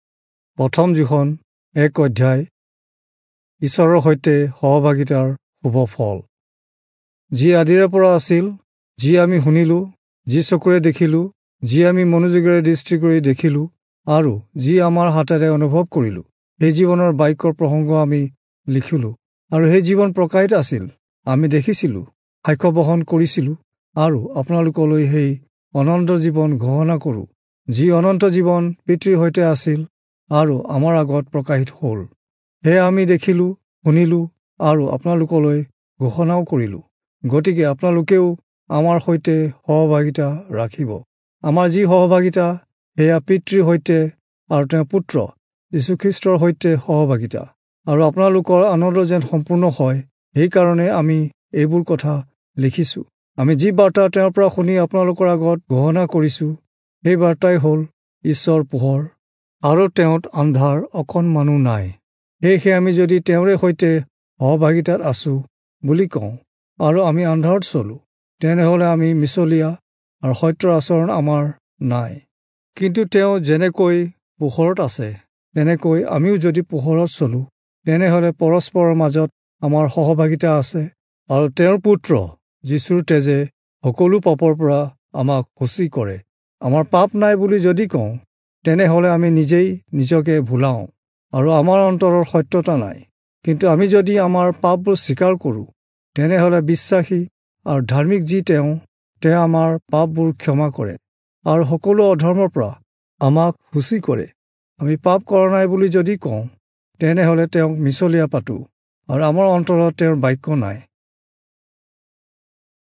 Assamese Audio Bible - 1-John 3 in Nlv bible version